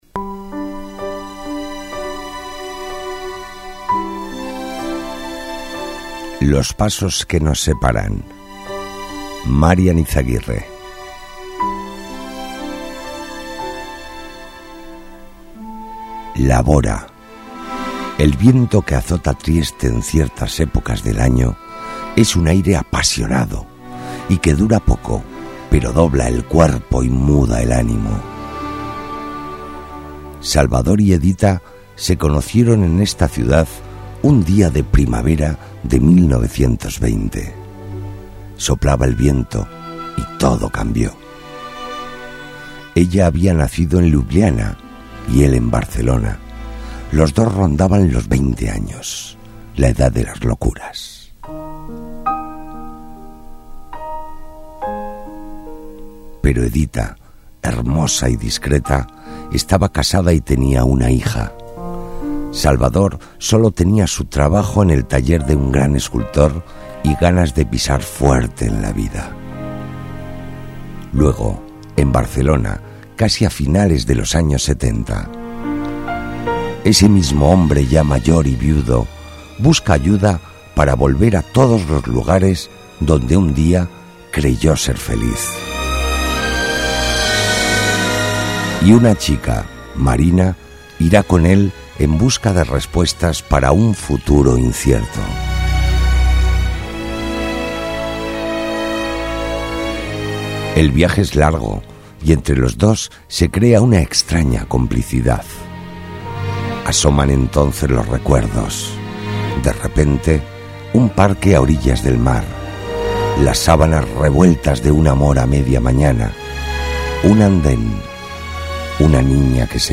Locutor profesional, más de 30 años de experiencia en radio, televisión , publicidad y márketing
kastilisch
Sprechprobe: Industrie (Muttersprache):